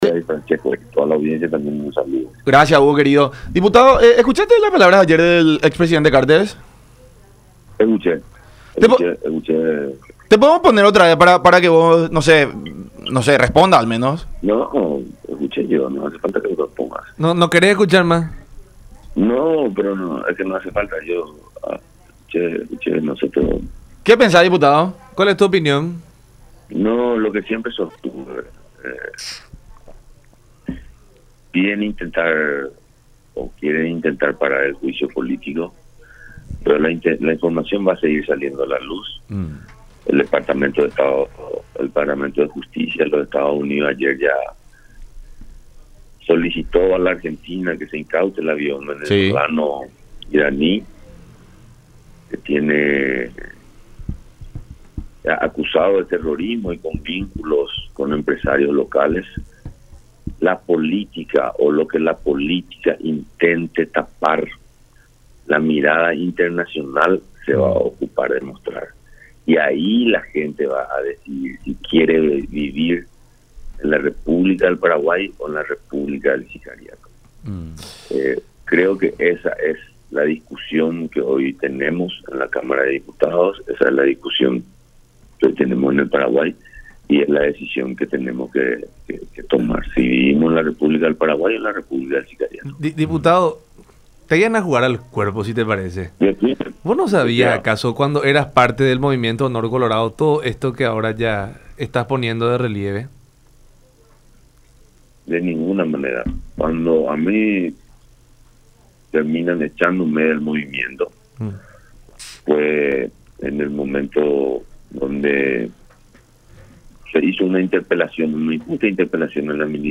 expresó Ramírez en charla con La Unión Hace La Fuerza por Unión TV y radio La Unión.